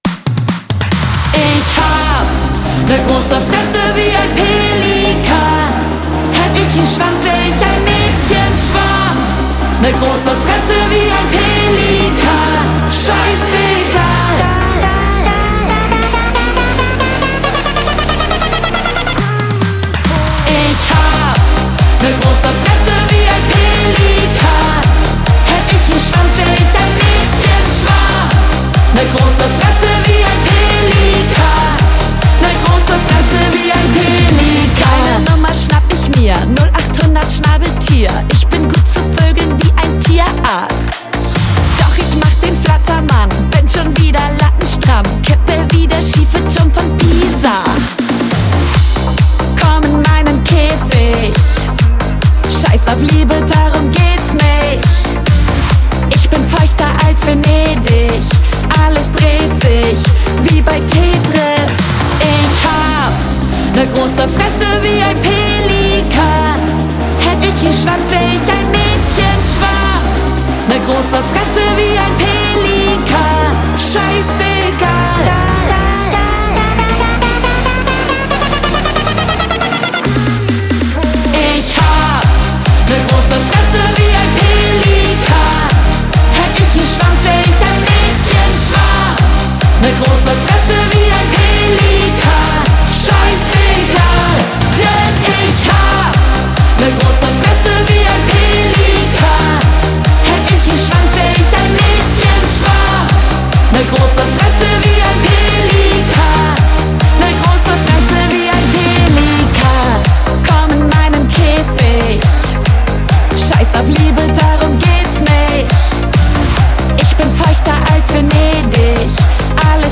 Frauen-Duo im deutschsprachigen Party-EDM
Crossover aus Partyschlager und elektronischerTanzmusik